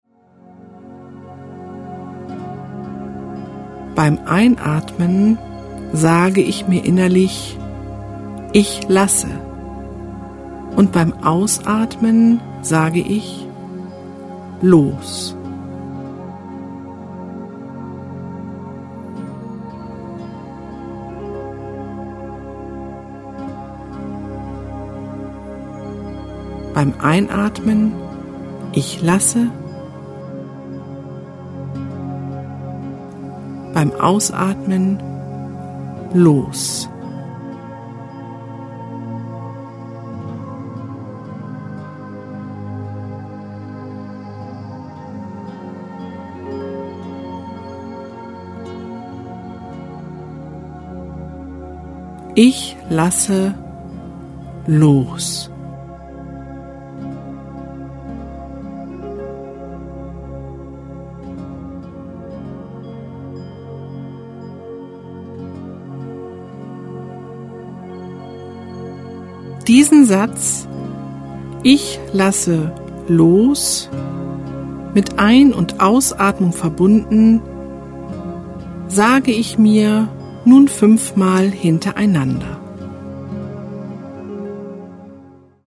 Die begleitende Musik bringt Sie wieder auf Ihre eigene Schwingungsfrequenz. Sie schwingt leicht und wirkt entspannend, belebend und stimuliert den Organismus.
Weibliche Stimme   11:06 min